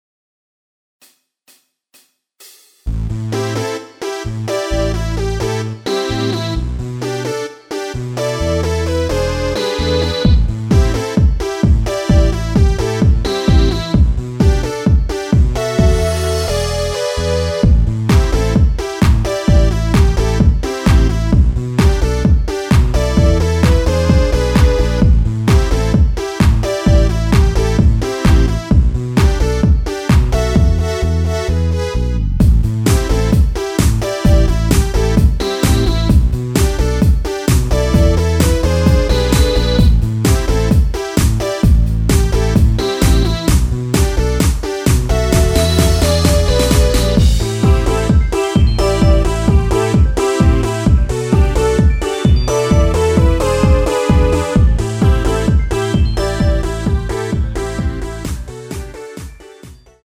Am
앞부분30초, 뒷부분30초씩 편집해서 올려 드리고 있습니다.